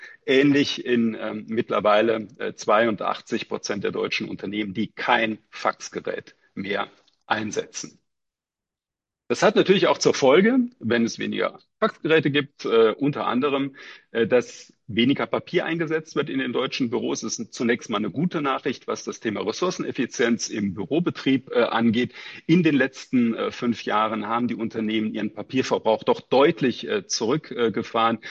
Mitschnitte der Pressekonferenz
pressekonferenz-digital-office-2025-fax-und-papier-in-deutschen-bueros.mp3